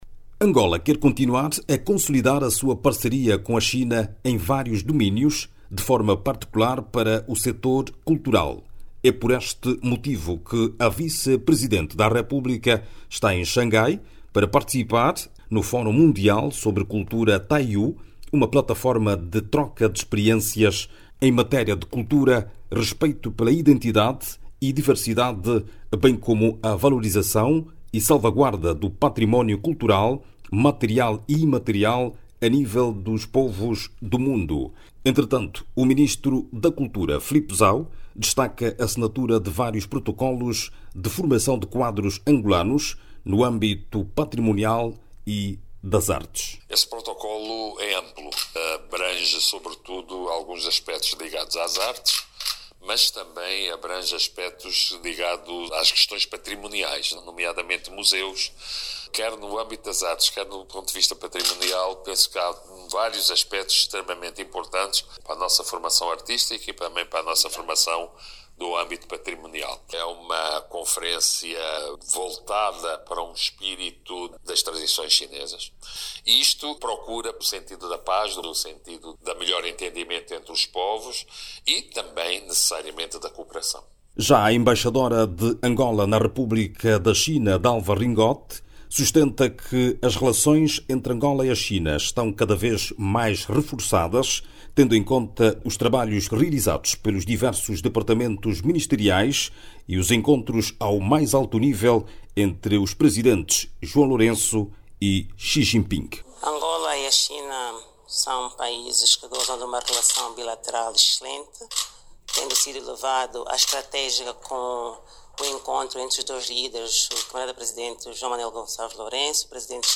A partir de Xangai, a reportagem